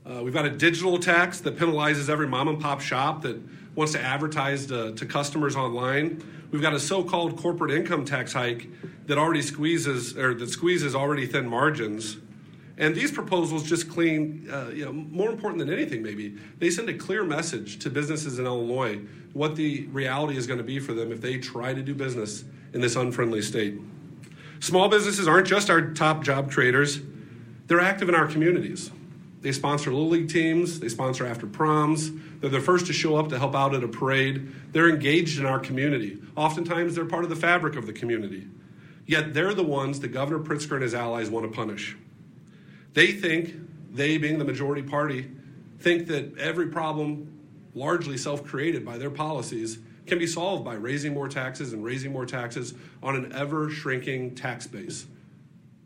Meanwhile, Senator Plummer talks more on the $6 million in new taxes.